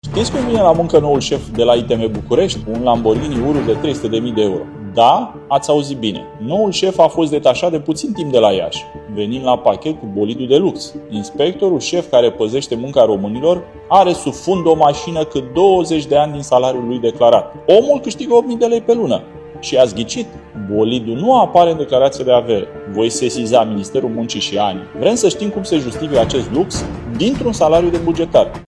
Deputatul USR Dumitru Văduva: „Vrem să știm cum se justifică acest lux dintr-un salariu de bugetar”